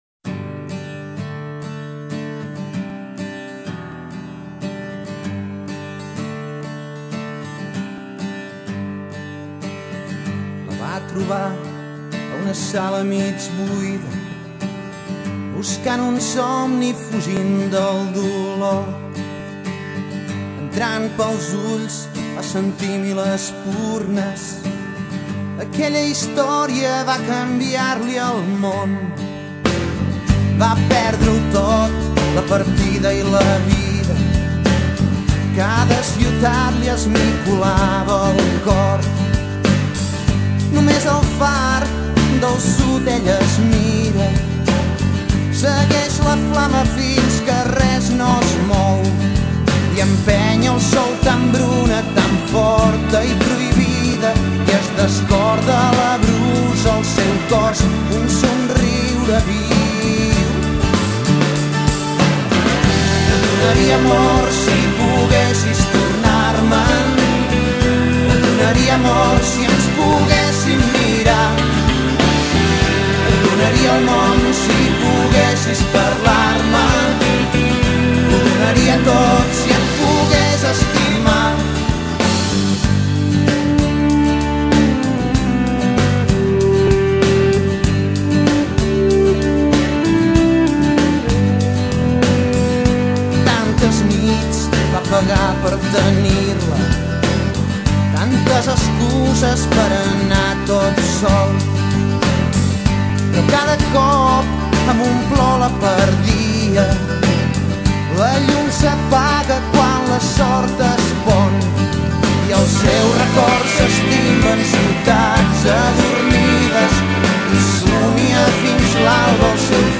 canção.